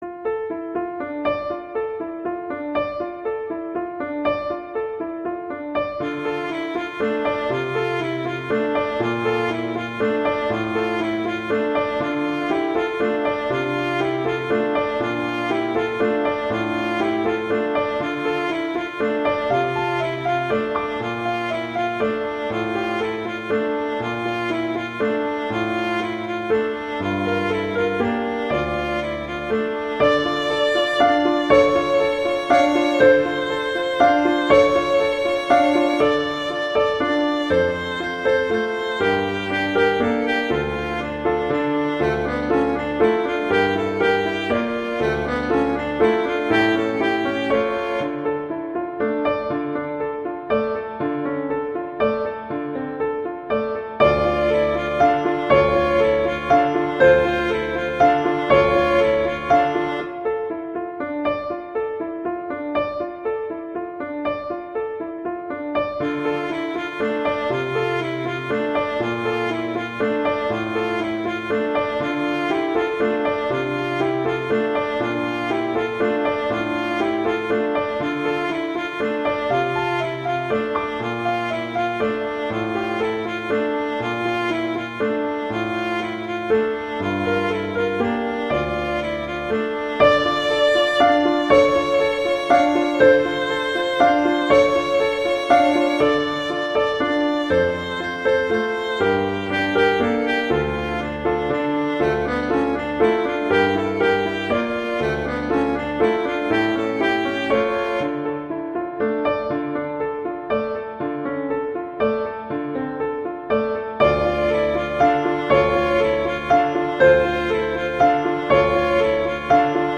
violin and piano